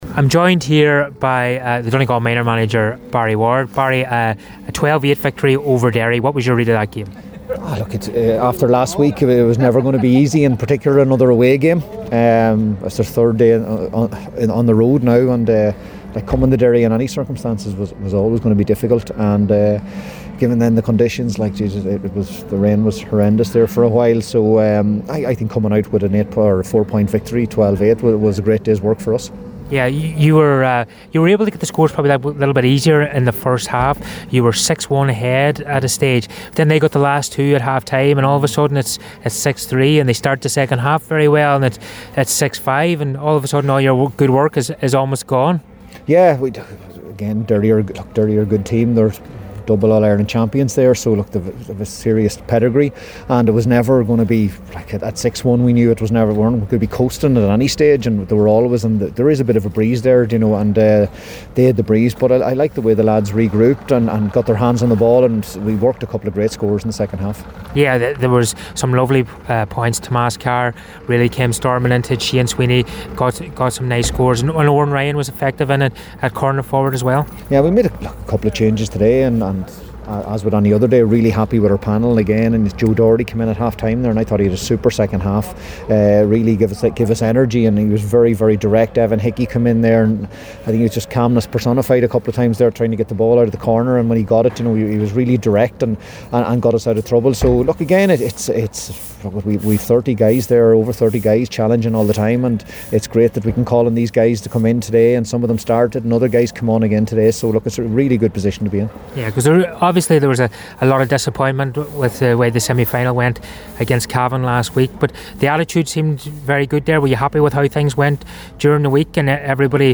After the game